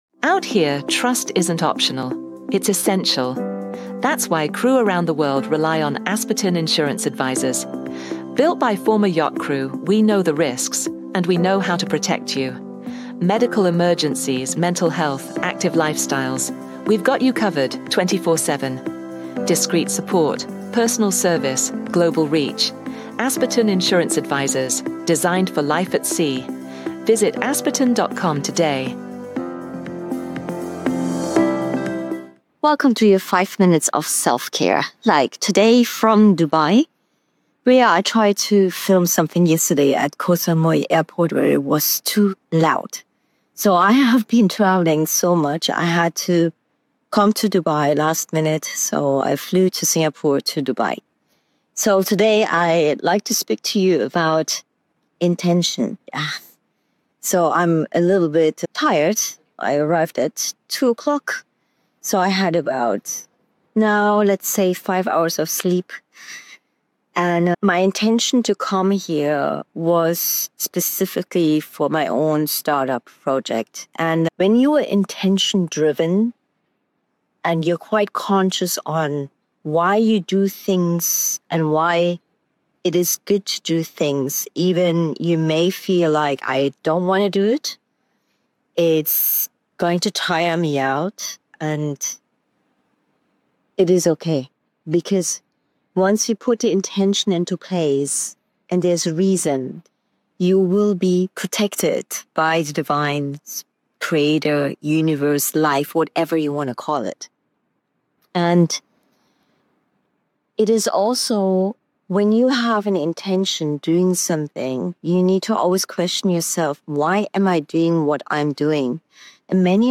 filmed during her travels from Singapore to Dubai